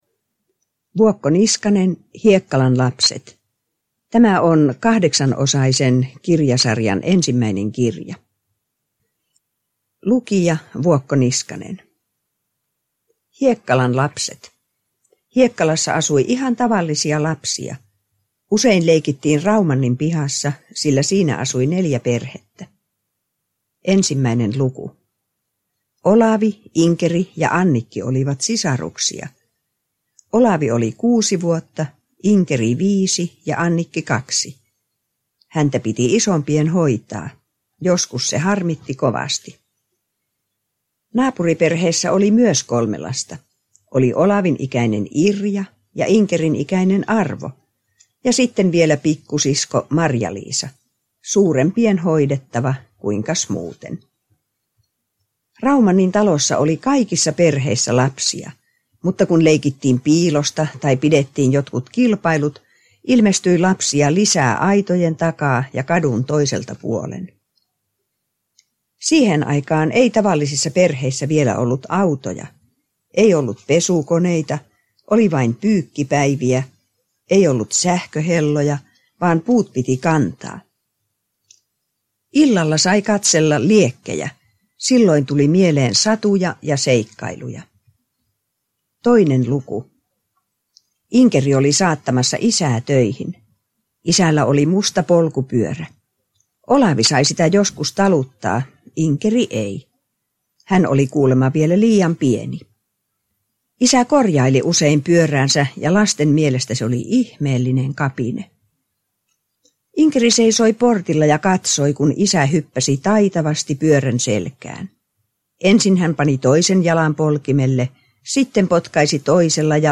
Hiekkalan lasten tarina – Ljudbok